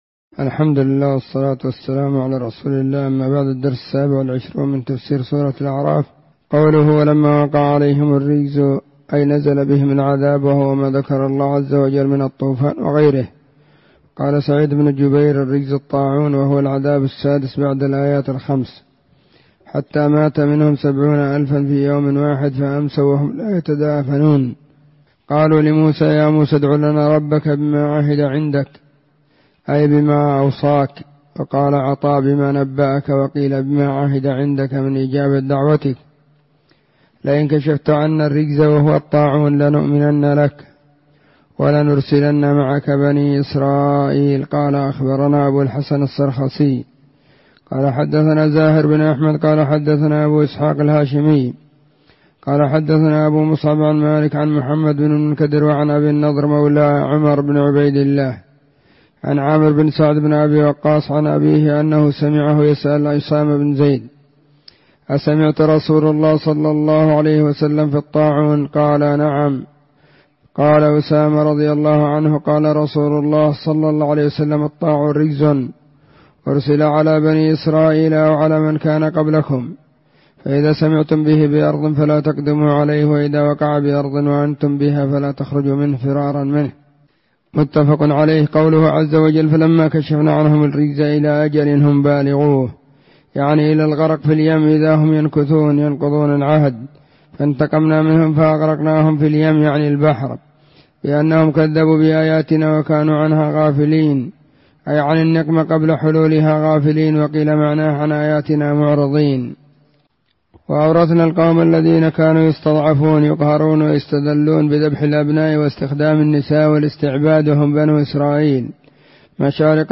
🕐 [بعد صلاة الظهر]
📢 مسجد الصحابة – بالغيضة – المهرة، اليمن حرسها الله.